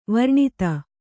♪ varṇita